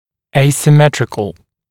[ˌeɪsɪ’metrɪk] [ˌэйси’мэтрик] асимметричный, несимметричный